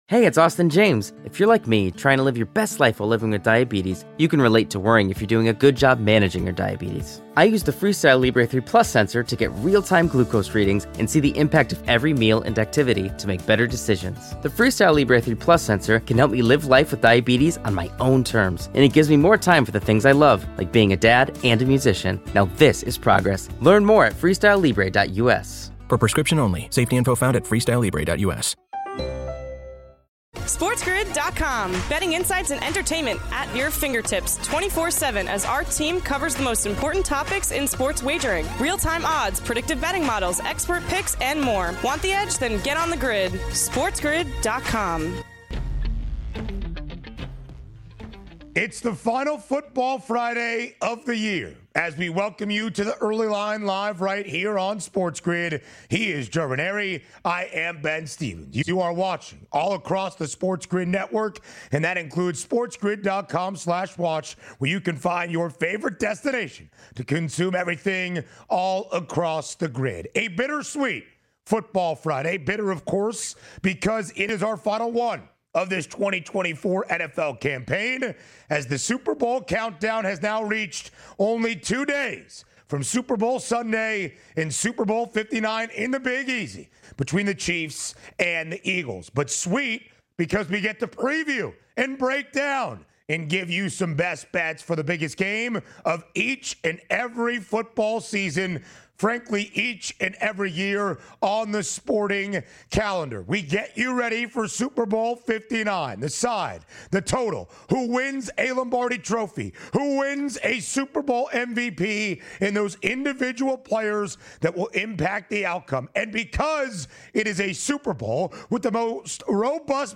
The hour rounds out with a sit-down with special guest Logan Ryan live from Radio Row!